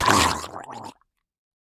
Minecraft Version Minecraft Version snapshot Latest Release | Latest Snapshot snapshot / assets / minecraft / sounds / mob / drowned / death2.ogg Compare With Compare With Latest Release | Latest Snapshot
death2.ogg